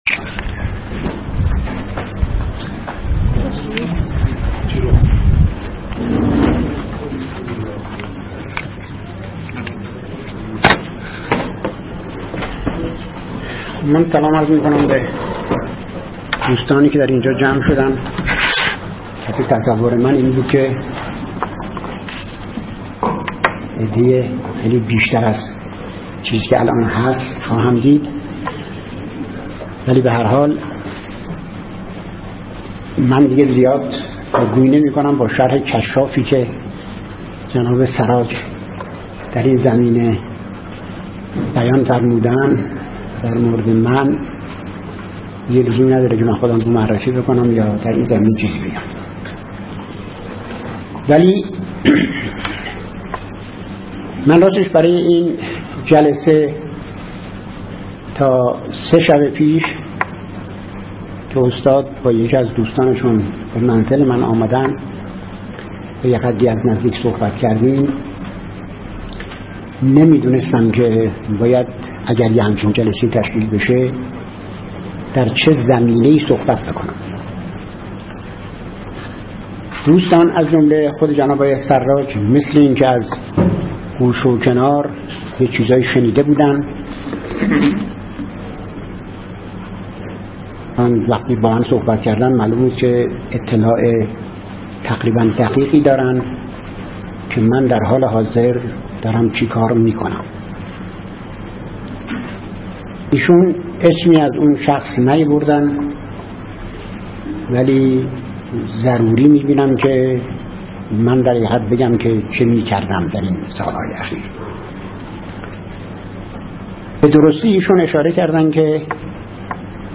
این نشست به همت گروه روش‌شناسی و تاریخنگاری پژوهشکده تاریخ اسلام خرداد ماه ۹۴ در این پژوهشکده برگزار شد.